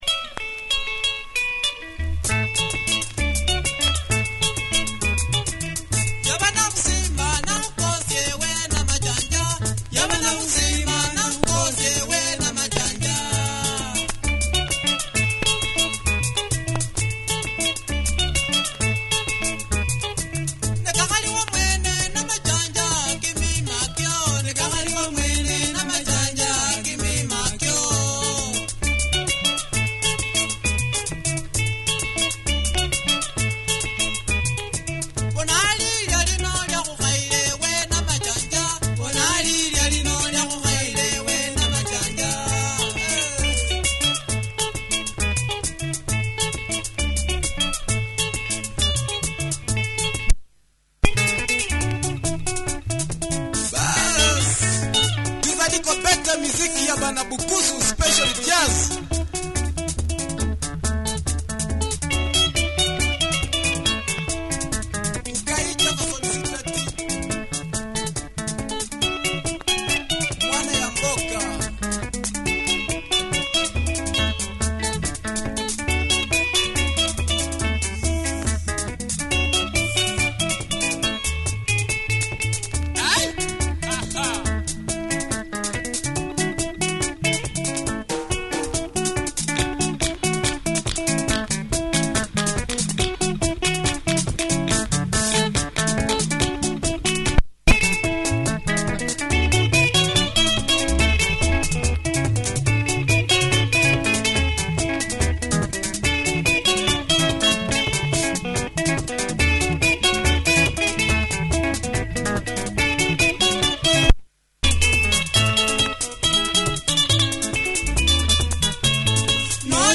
Tight Luhya benga